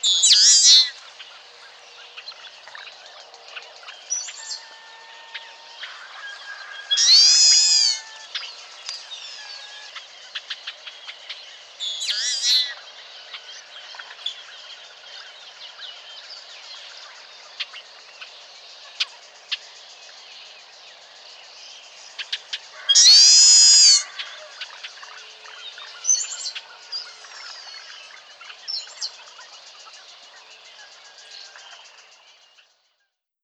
Agelasticus (Agelaius) thilius - Alférez